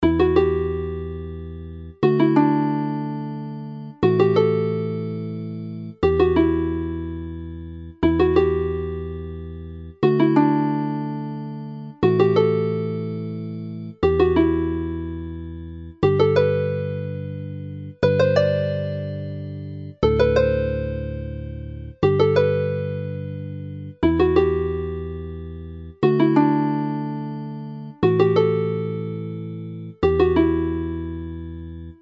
The haunting air Lorient which starts this set can be heard around the streets of Lorient during the festival as a tuning-up routine used in warming up by the pipers in Breton Bagapipe bands.